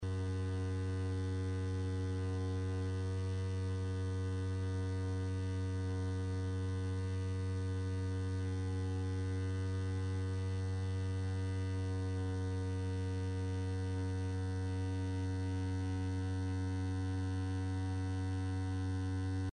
Звуки фонарей
Скачивайте или слушайте онлайн эффекты зажигания, ровного гудения и тихого потрескивания.